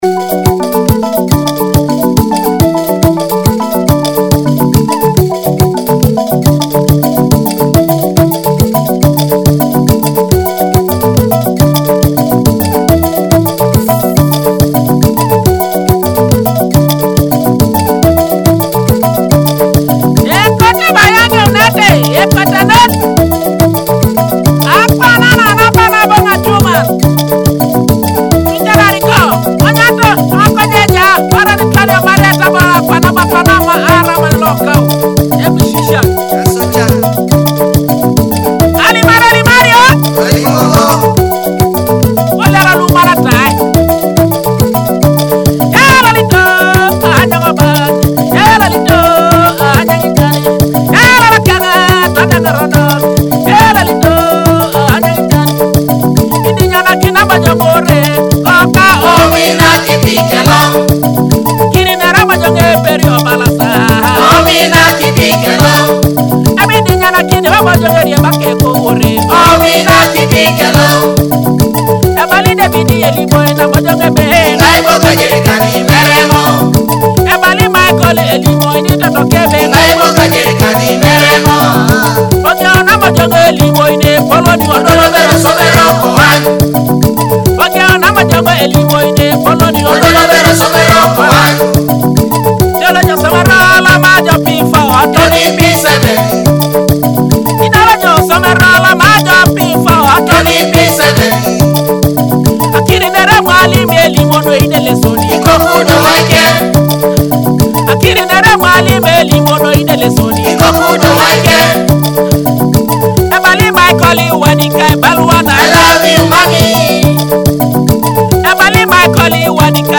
Teso rhythms in Akogo & Adungu